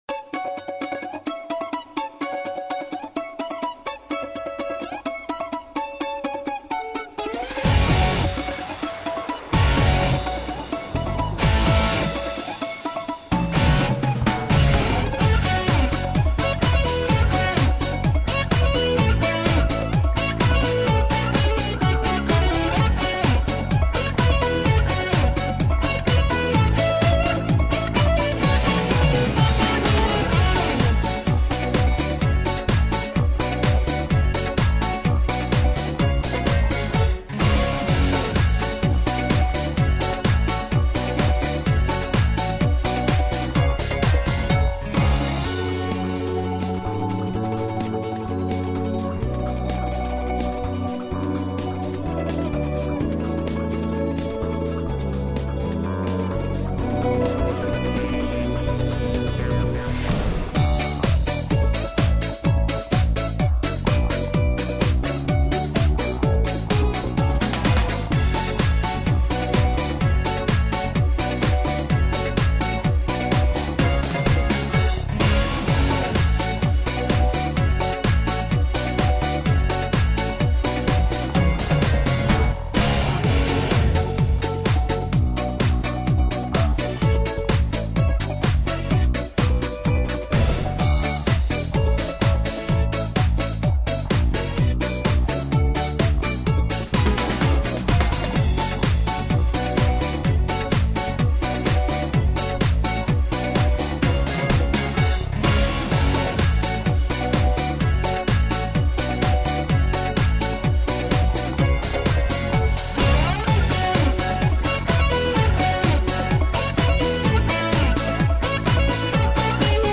минусовка версия 217428